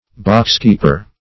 Boxkeeper \Box"keep`er\, n. An attendant at a theater who has charge of the boxes.